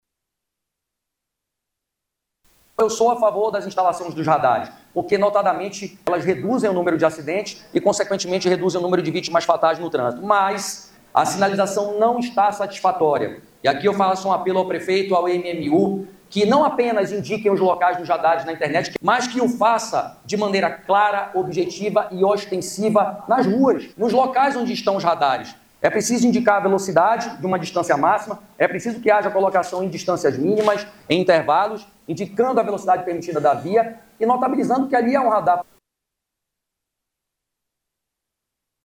O vereador, delegado Rodrigo Sá, utilizou a tribuna da Câmara de Vereadores de Manaus para declarar apoio a implantação dos radares, mas destaca que falta sinalização clara e justa para todos.  De acordo com o parlamentar, se o objetivo é educar e prevenir, não faz sentido esconder a informação.